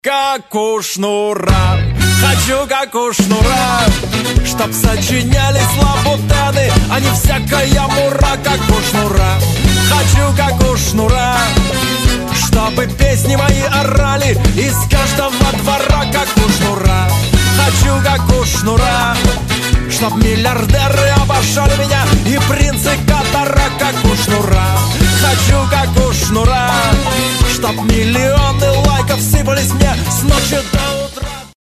• Качество: 256, Stereo
веселые